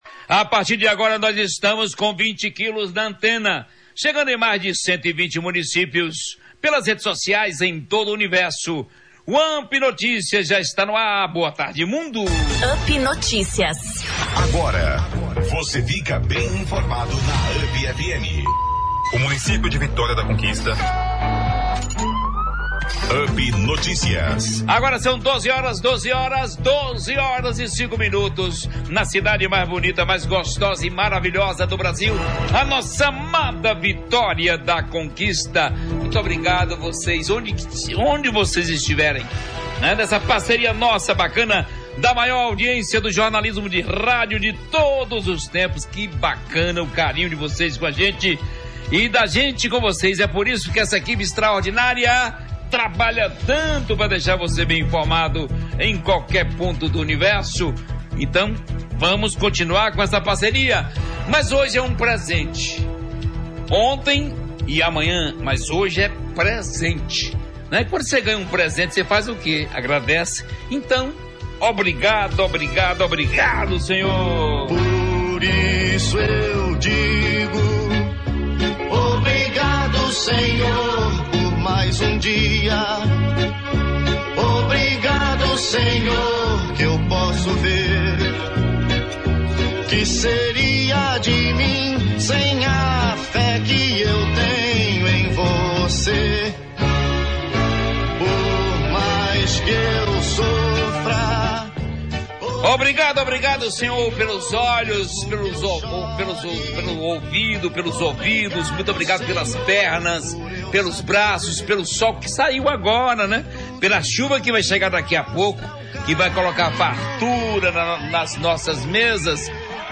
Dois ícones da Radiofonia Conquistense se reencontraram no estúdio da Rádio UP.